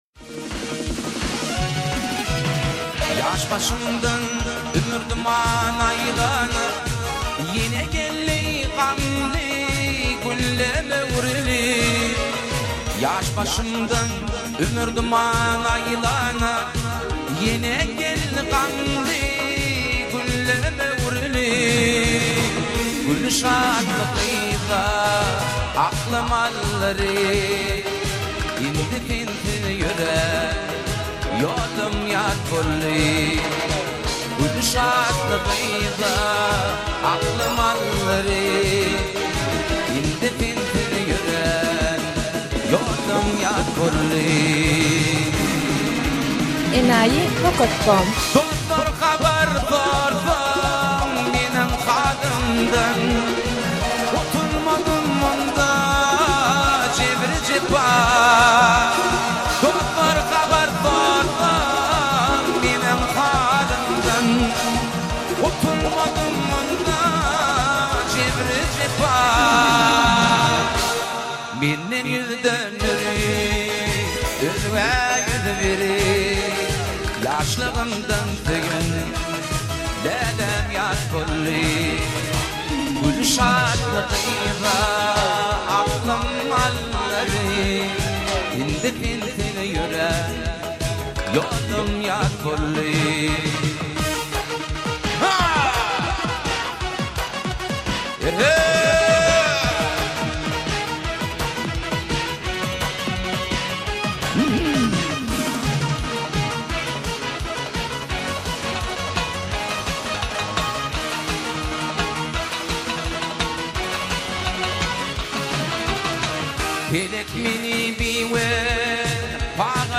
Туркменские песни